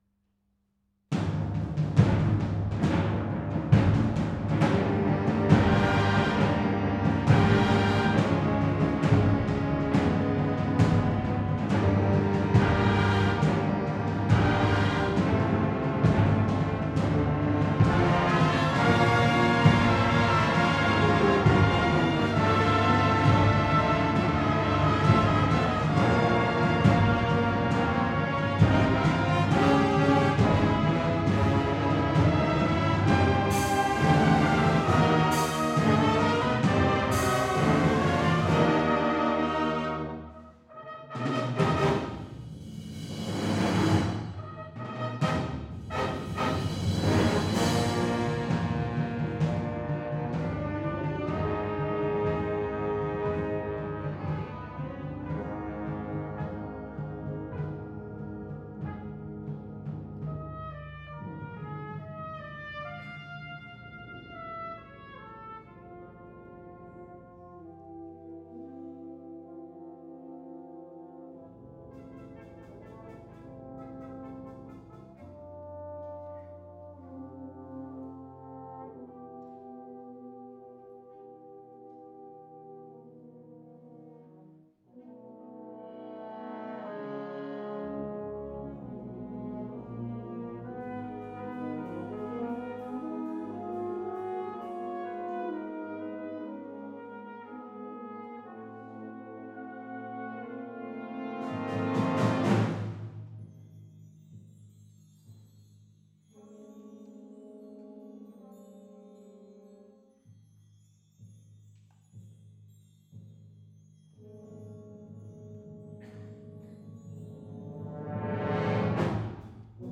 Musique – Fanfare La Cécilia d'Ardon
Pit Stop at Purgatory de Ludovic Neurohr, pièce imposée jouée par la Cécilia lors de la fête cantonale 2014 /wp-content/uploads/2013/11/Ardon_Cecilia_Pit_Stop_at_Purgatory.mp3